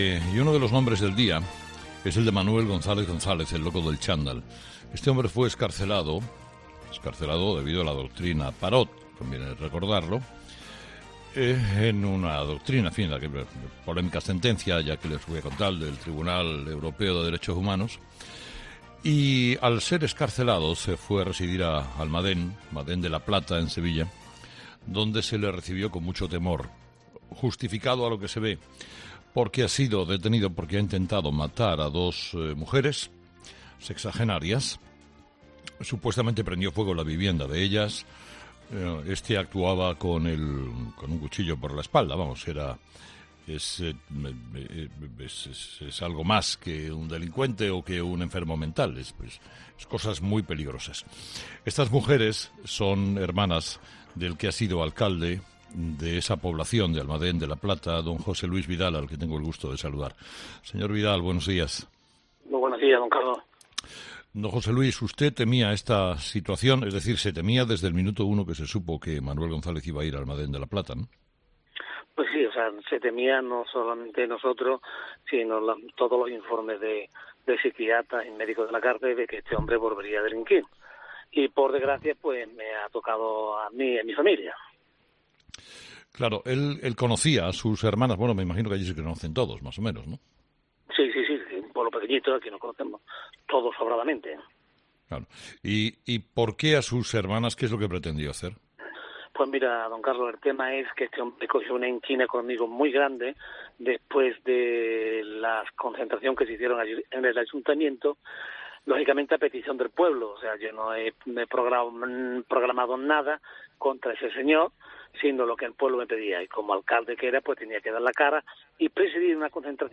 Escucha a José Luis Vidal, ex alcalde de Almadén de la Plata